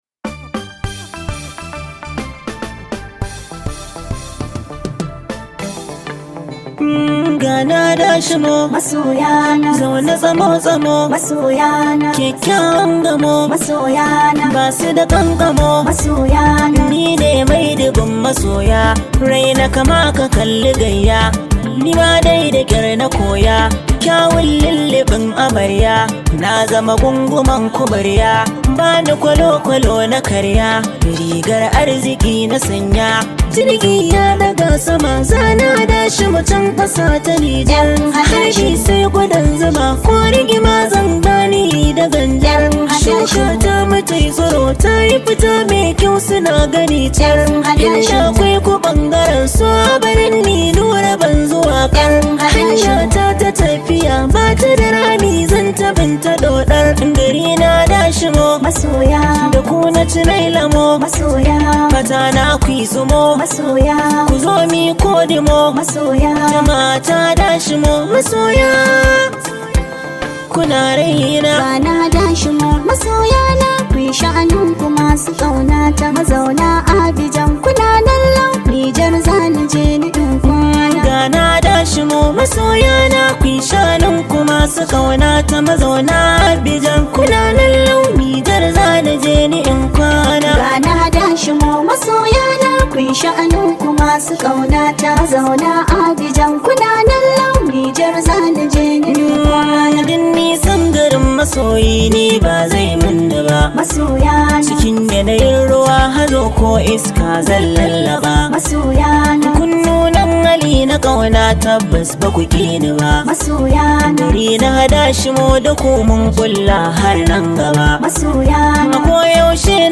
Hausa Songs
Hausa Singer